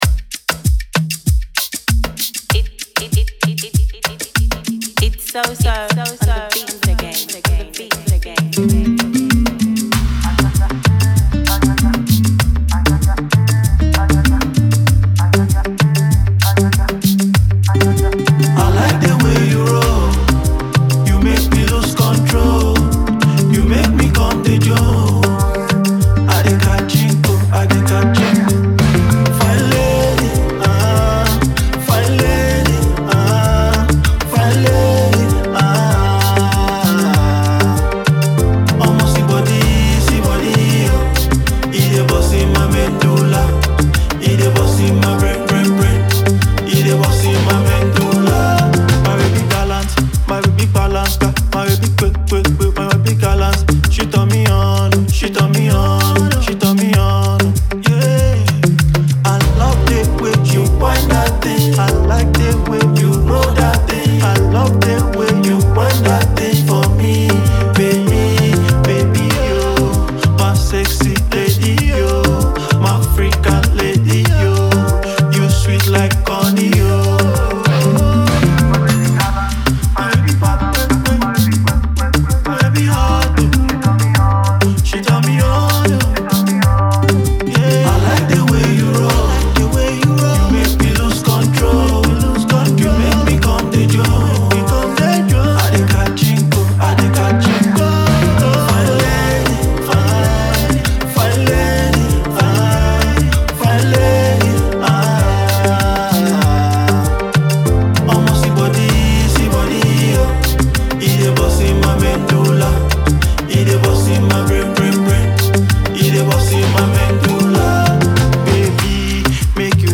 catchy single